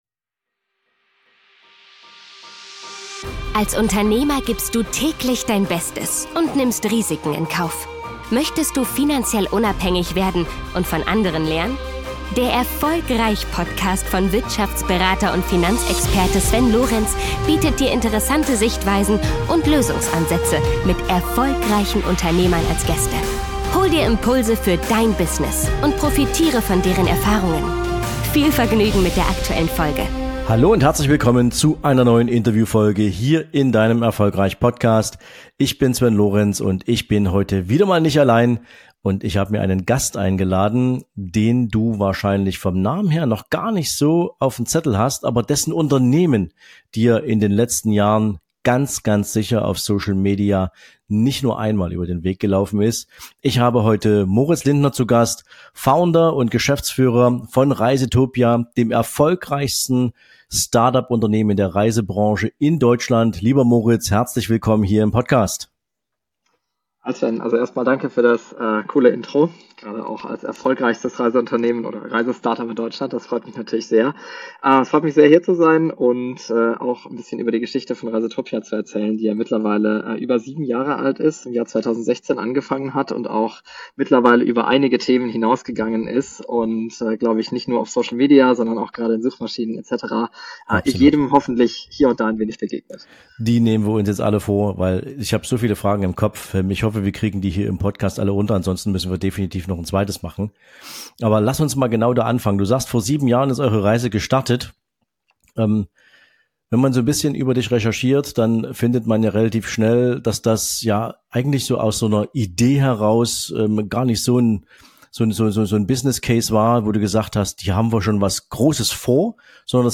In einem offenen Gespräch lassen wir euch teilhaben an seiner inspirierenden Geschichte, von den bescheidenen Anfängen eines Hobbyprojekts bis hin zum Durchbruch als einflussreiches Reiseunternehmen.